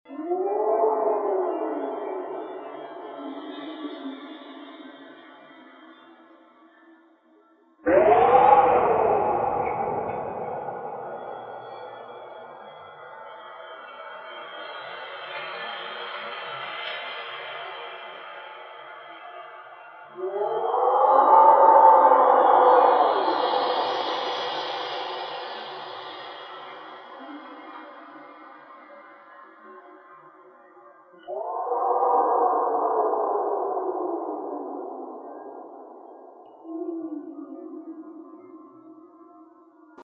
wind whistle.mp3